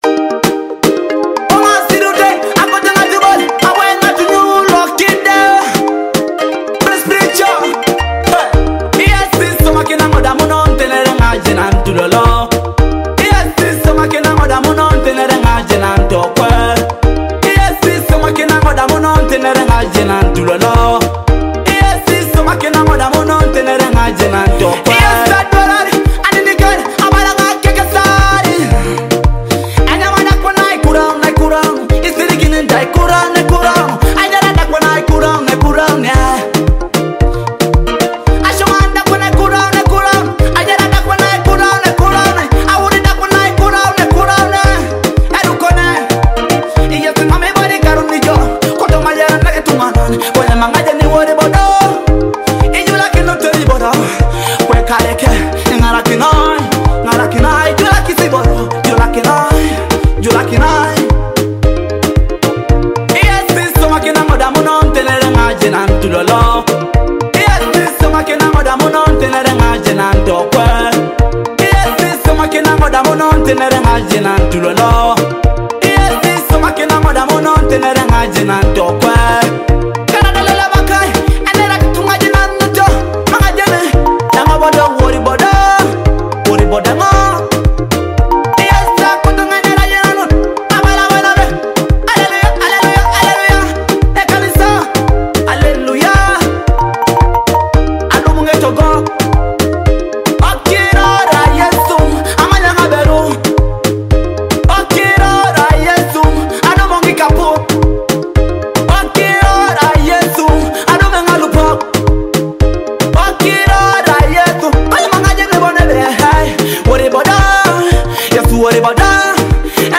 soul-stirring gospel song
With deep emotion and powerful lyrics